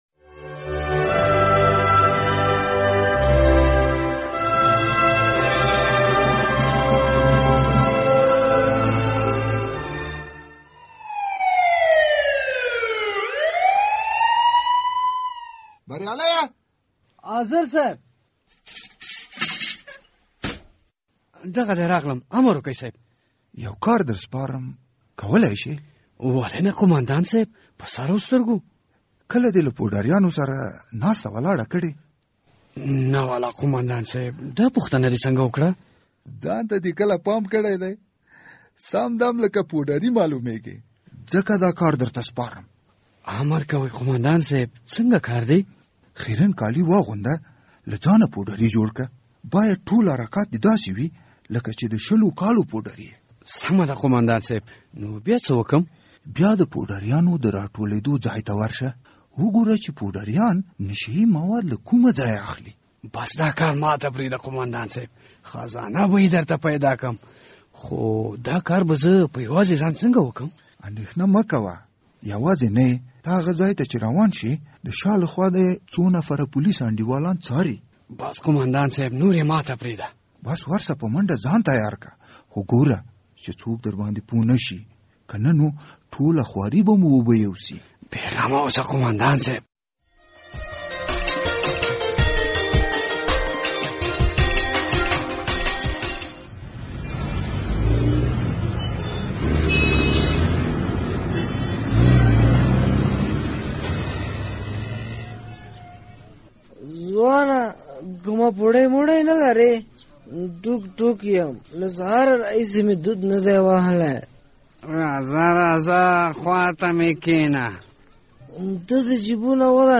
د زهرو کاروان ډرامه